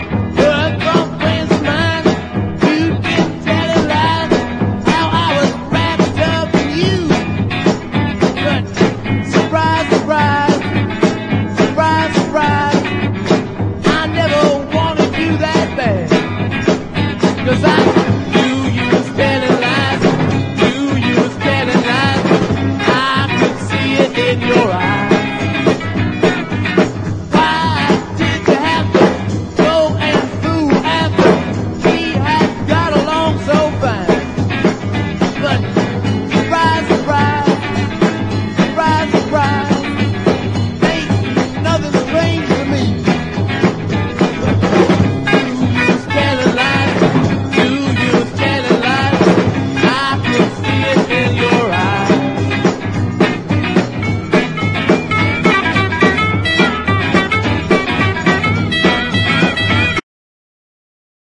EASY LISTENING / EASY LISTENING / LATIN / LATIN LOUNGE
日本の楽曲をジャズ＆ジャイヴなアレンジでラテン・カヴァーした和モノ